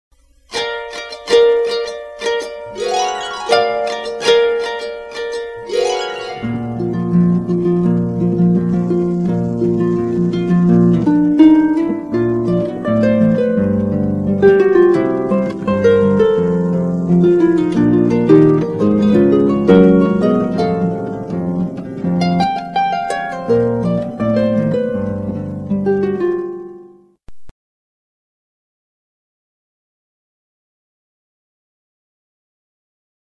Used by Permission Pedal Harp...